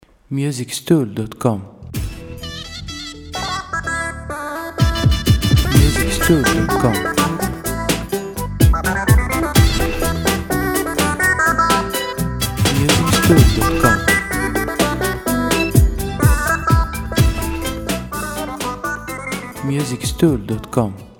• Type : Instrumental
• Bpm : Allegro
• Genre : Oriental / Folk & Country